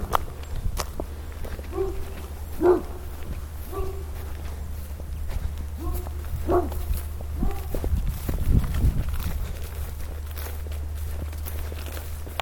Sounds recorded on a walk around the village in June